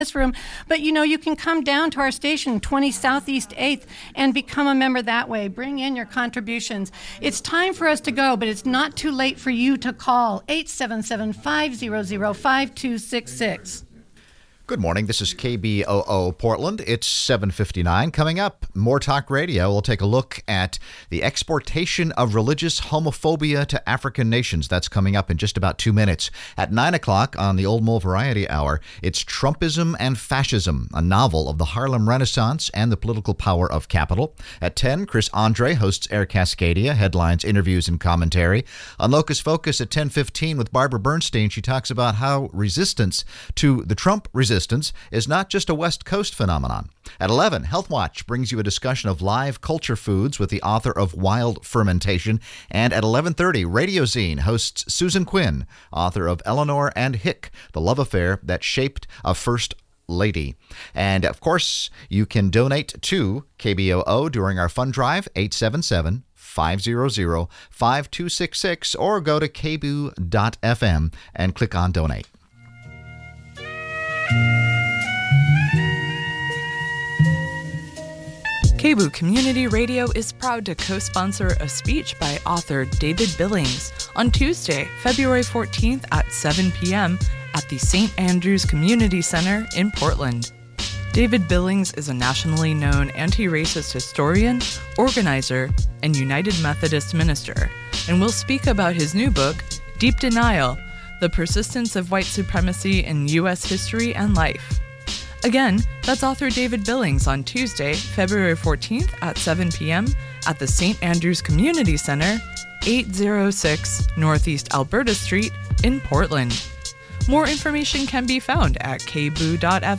More Talk Radio